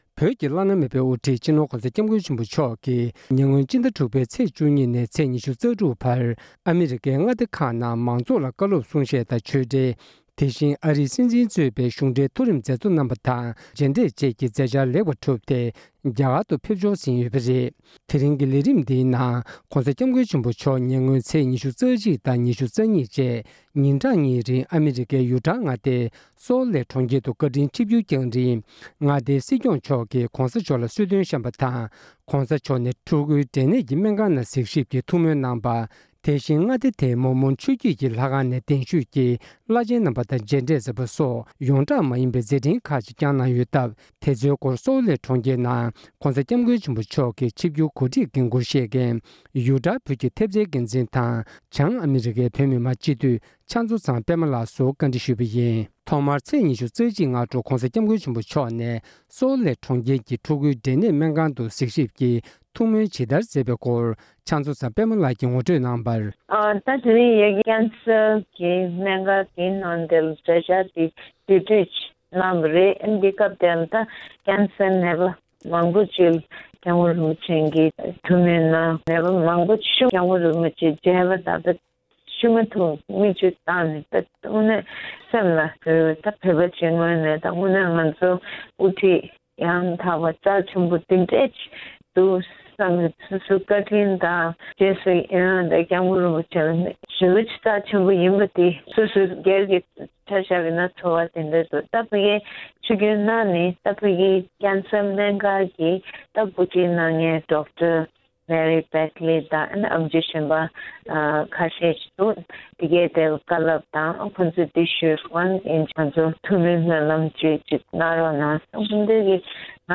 གོང་ས་མཆོག་གིས་ཉེ་ཆར་ཨ་རིའི་Salt Lakeགྲོང་ཁྱེར་དུ་ཆིབས་བསྒྱུར་སྐབས་ ཡོངས་གྲགས་མ་ཡིན་པའི་མཛད་འཕྲིན་སྐོར། སྒྲ་ལྡན་གསར་འགྱུར།